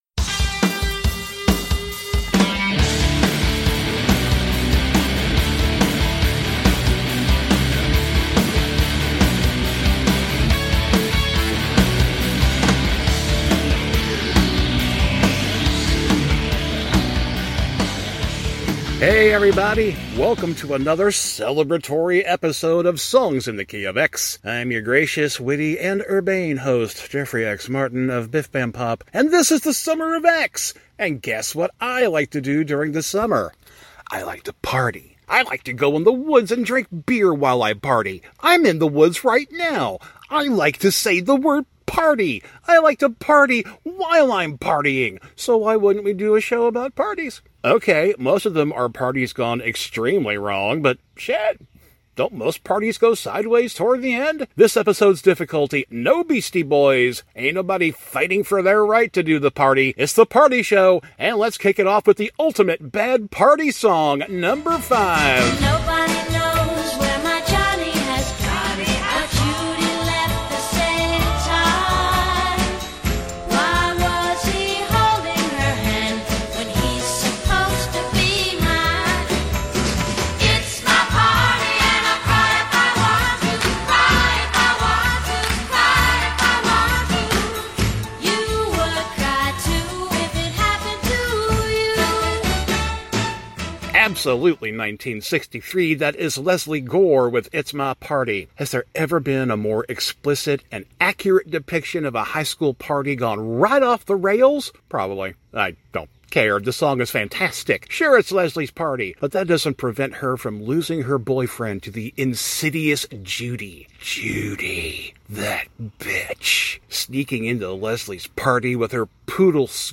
He’ll tell you his five favorite party songs! Thrill to the tale of the second worst party he ever attended! Most of all, groove to some music that will kick off a celebration in your earholes!